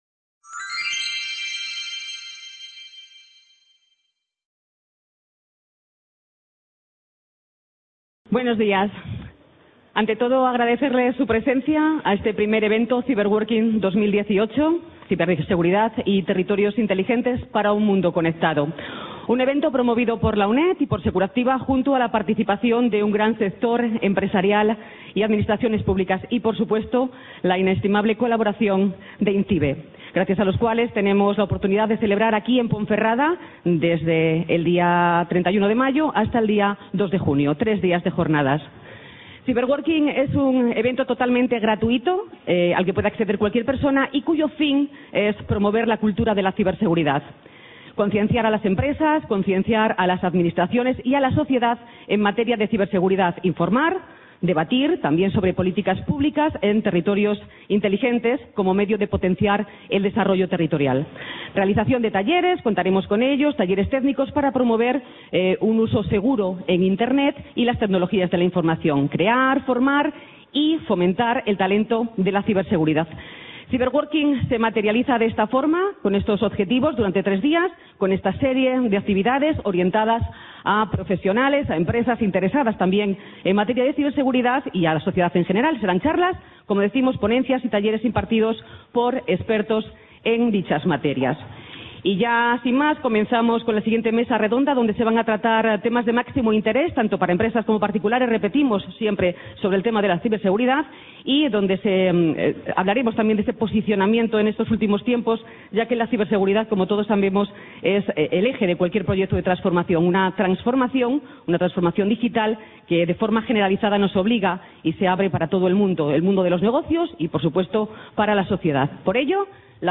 Mesa Redonda – La Ciberseguridad y las oportunidades…
CA Ponferrada - CYBERWORKING 2018, Ciberseguridad y Territorios Inteligentes para un Mundo Conectado